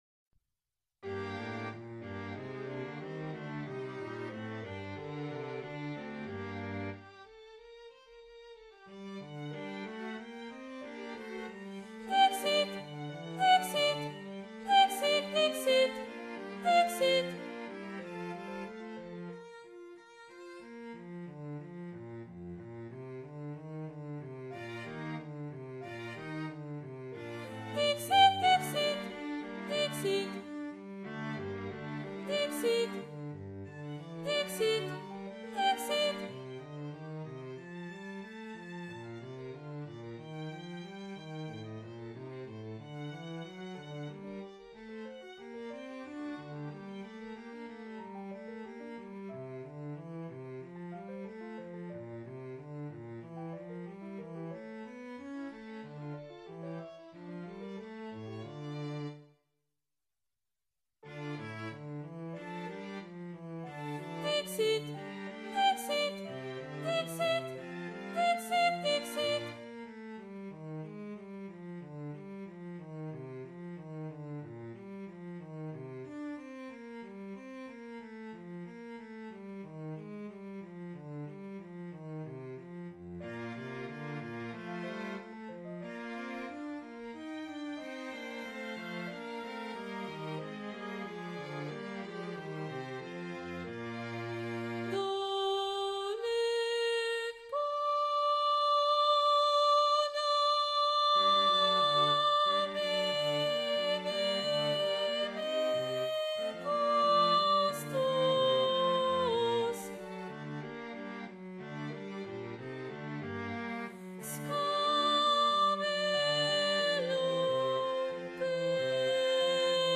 Below you will find rehearsal recordings of the movements, arranged in the order they will be sung at the concerts on December 13 and 14, 2025.
Sopranos
Emphasised voice and other voices